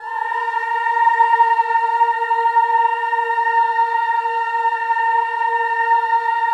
VOWEL MV14-L.wav